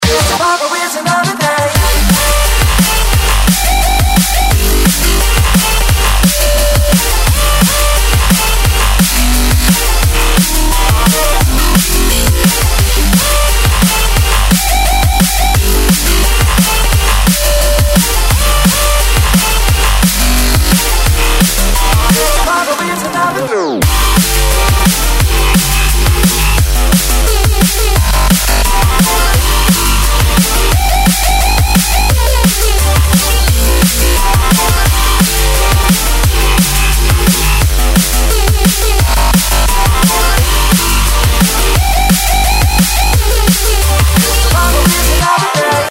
electro